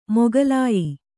♪ mogalāyi